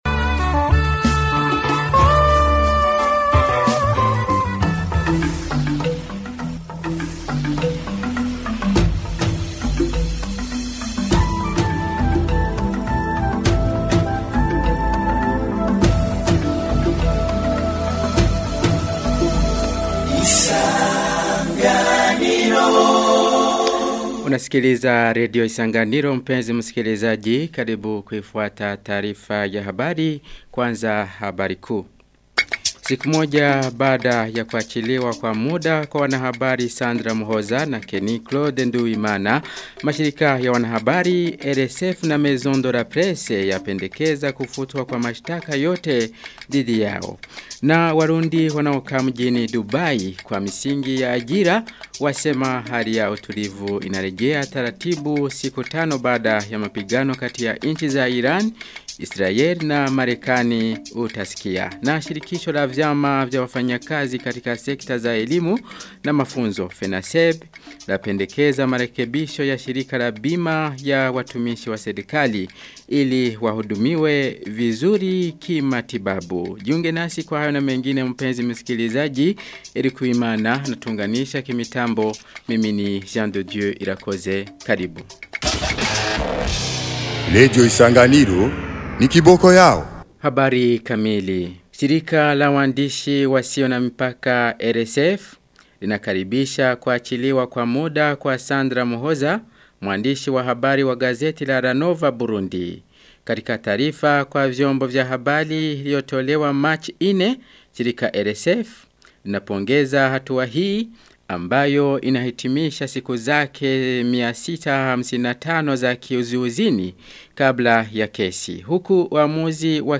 Taarifa ya habari ya tarehe 5 Marchi 2026